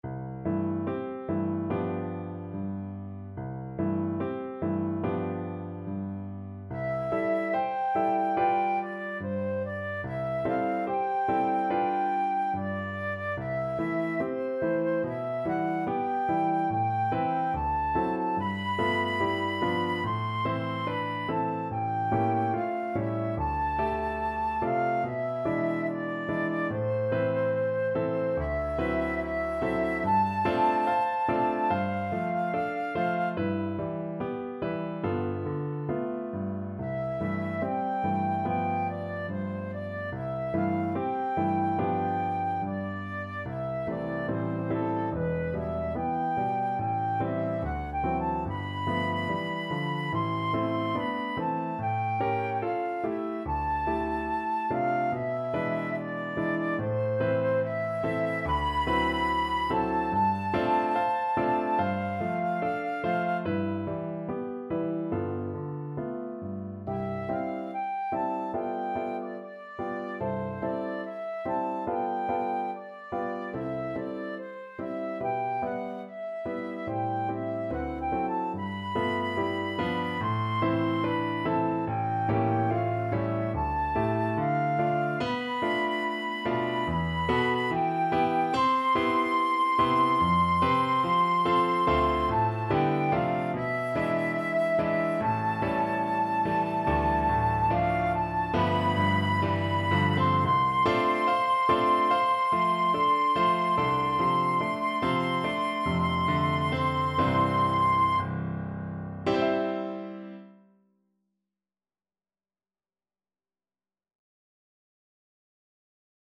Flute version
~ = 72 In moderate time
4/4 (View more 4/4 Music)
Classical (View more Classical Flute Music)